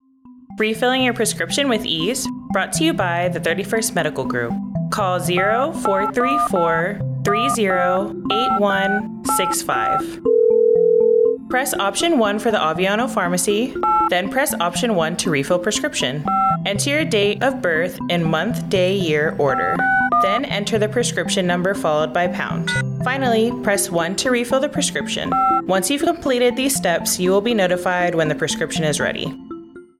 An American Forces Network Aviano radio spot providing steps to use the base pharmacy’s prescription refill phone line at Aviano Air Base, Italy. Wyverns can refill prescriptions with the click of a few buttons by using the prescription phone line.